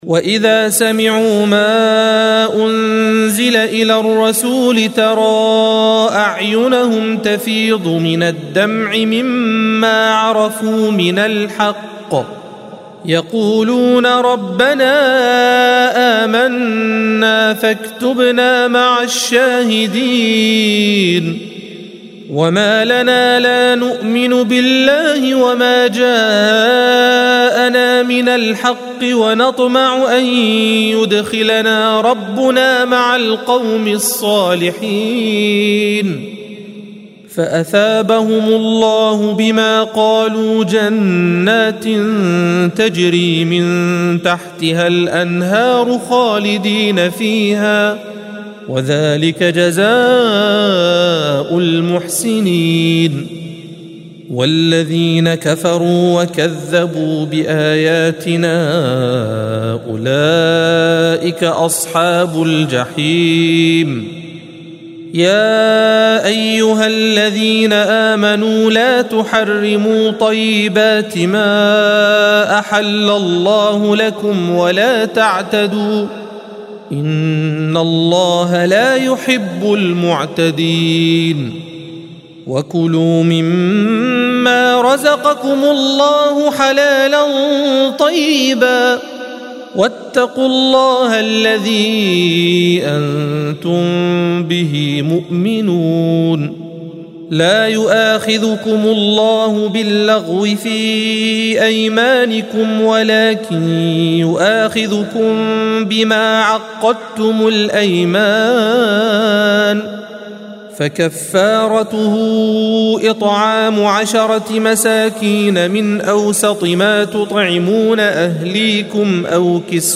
الصفحة 122 - القارئ